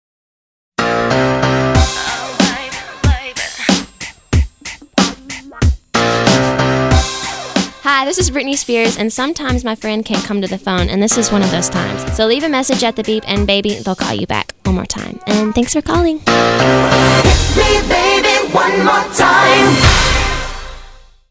Britney Spears Answering Machine Greeting, Official Recording!